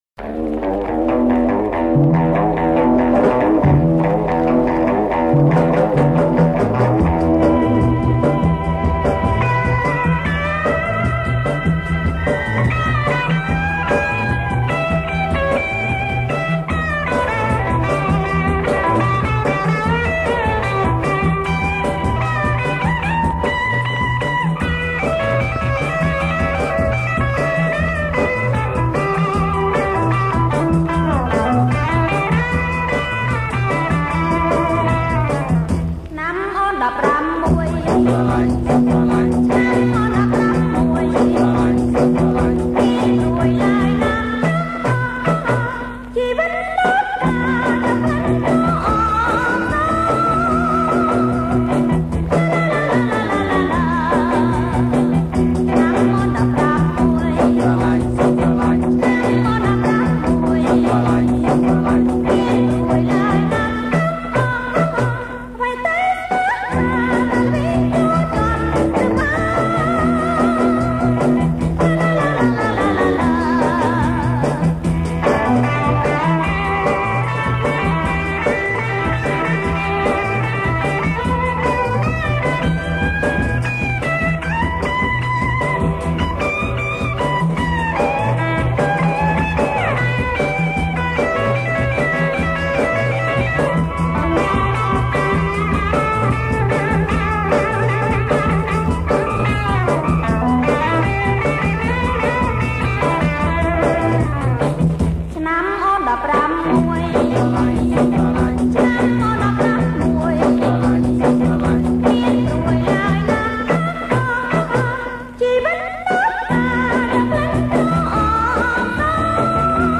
• ប្រគំជាចង្វាក់ Twist